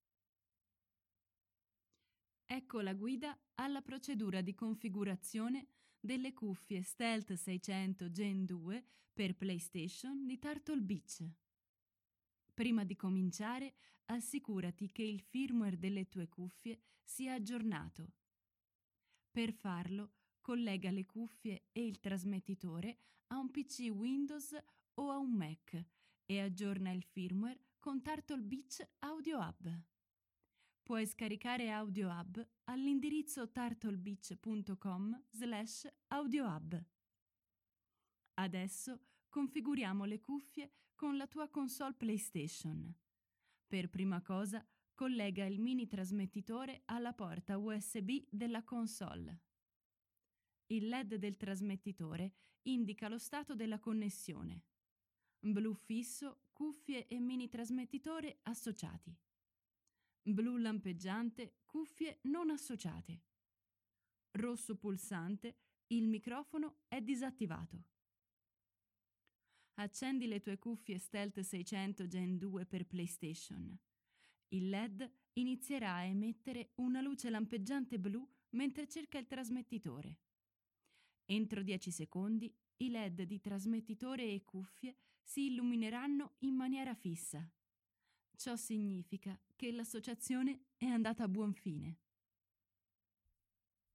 voce giovane, brillante, calda, versatile per spot, documentari, doppiaggi, audiolibri e quant'altro.
Sprechprobe: eLearning (Muttersprache):